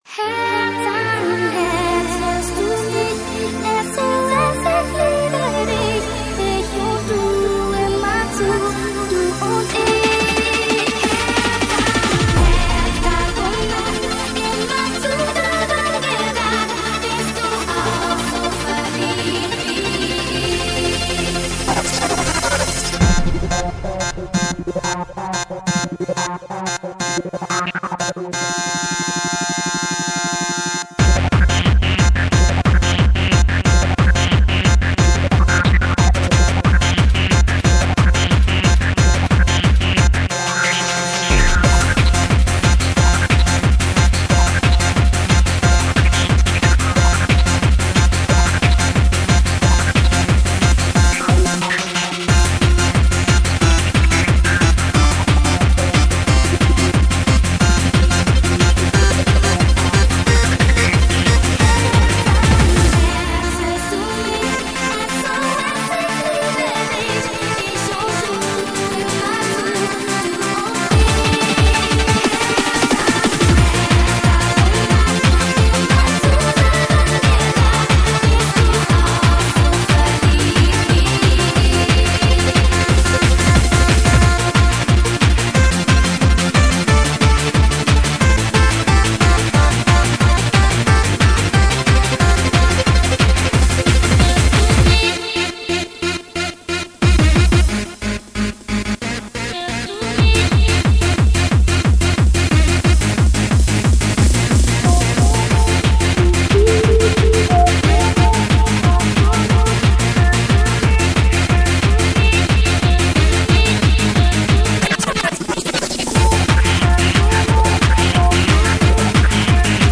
рэйв-версию